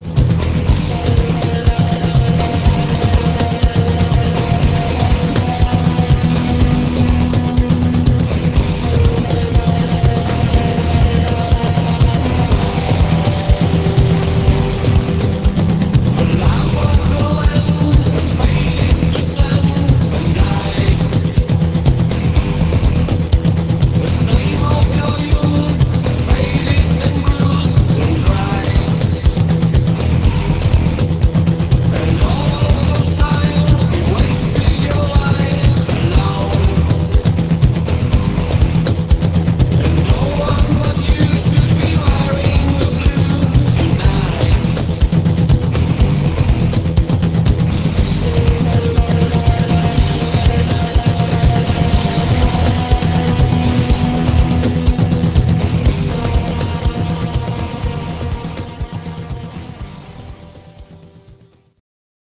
124 kB MONO